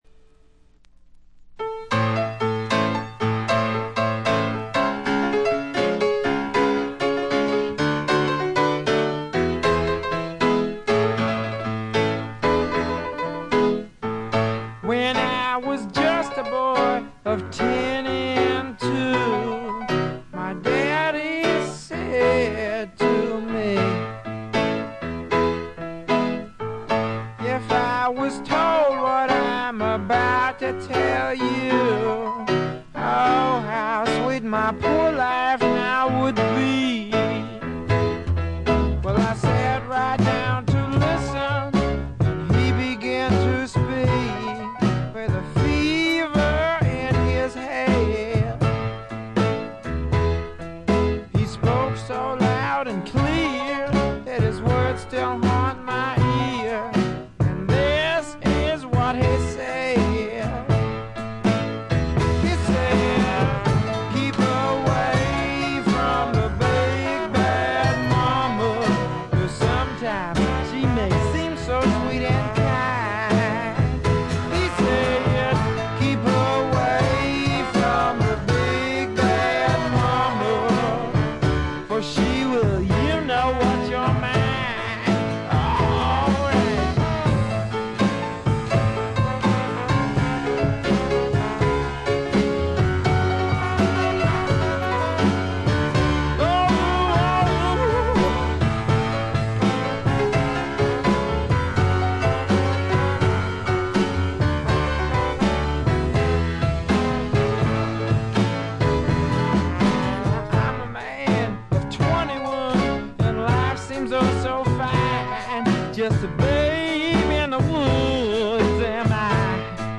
軽微なチリプチ少々、散発的なプツ音が少し。
まさしくスワンプロックの理想郷ですね。
試聴曲は現品からの取り込み音源です。
Vocals, Acoustic Guitar, Piano, Violin